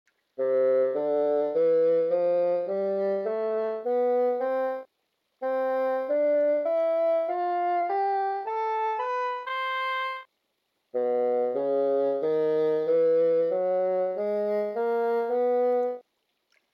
Lonely_Mountain_Bassoon_Sound.mp3